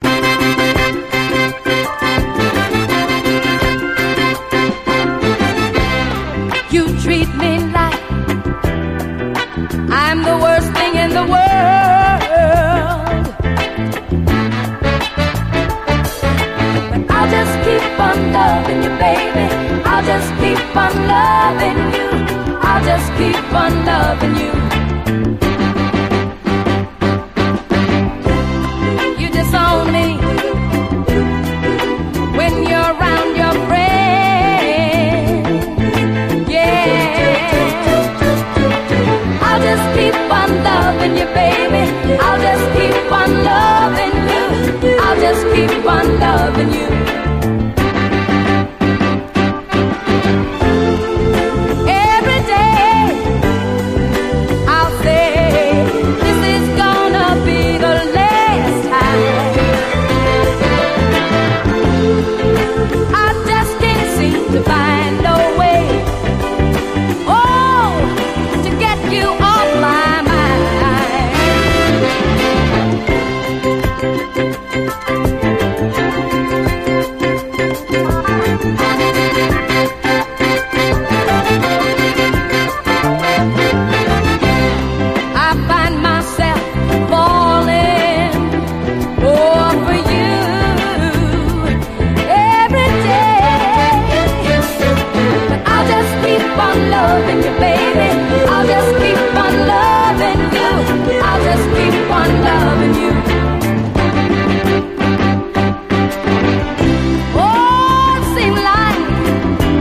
SOUL, 70's～ SOUL